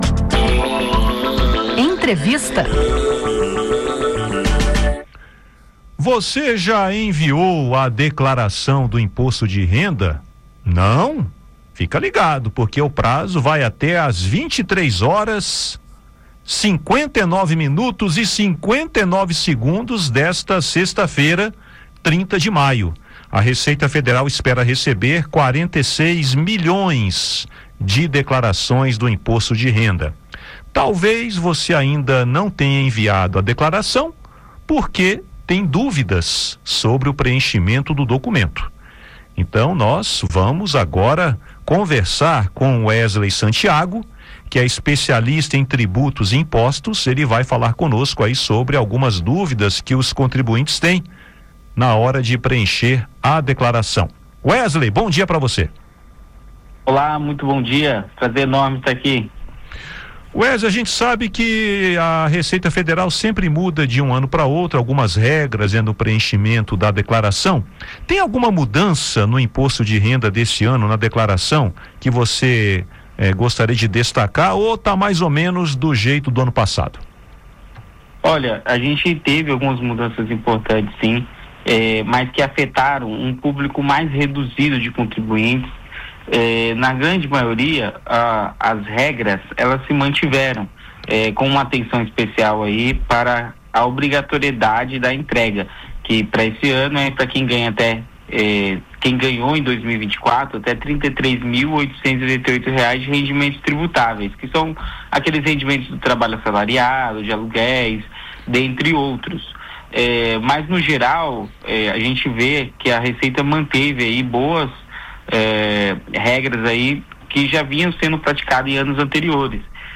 Especialista em tributos e impostos esclarece principais dúvidas sobre o preenchimento da declaração do IR